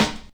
Snare (41).wav